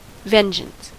Ääntäminen
US : IPA : [ˈvɛnˌdʒəns]